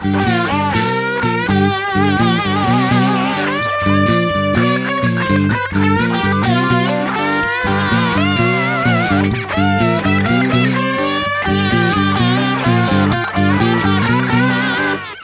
Index of /90_sSampleCDs/Best Service ProSamples vol.17 - Guitar Licks [AKAI] 1CD/Partition D/VOLUME 007